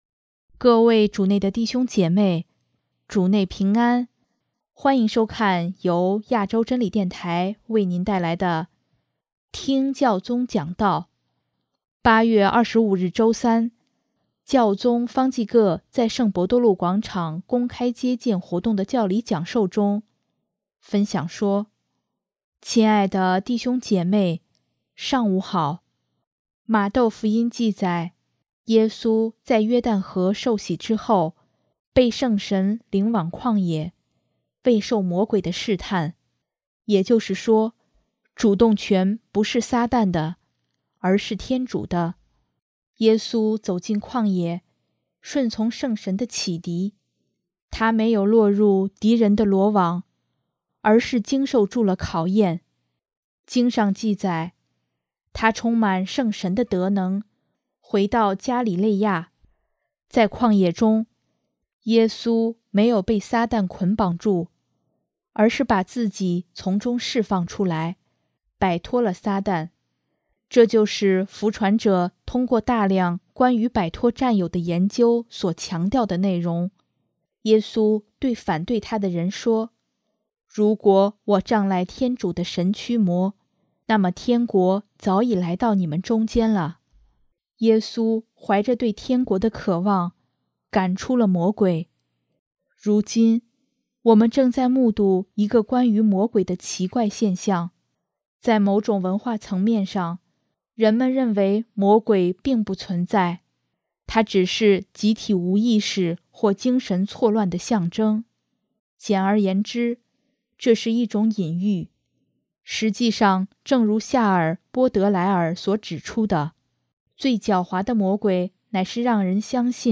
8月25日周三，教宗方济各在圣伯多禄广场公开接见活动的教理讲授中，分享说：